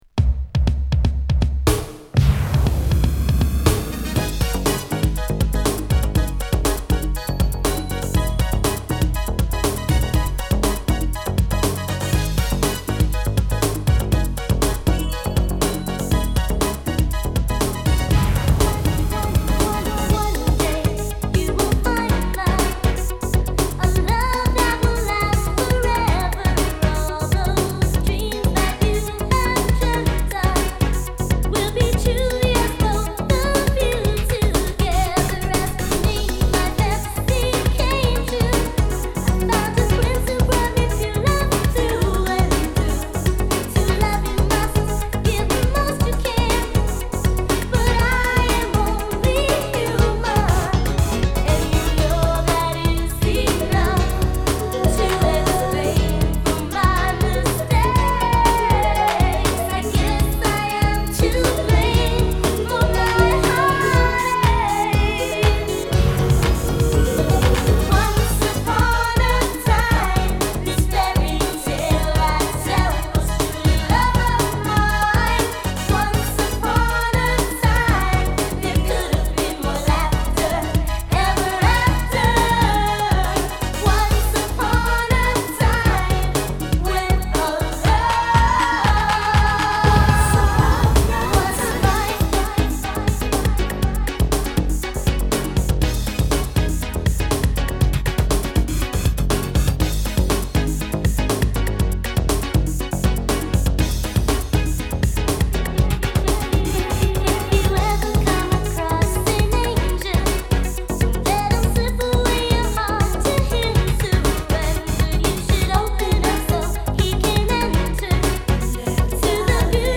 N.Y.発のガールズグループ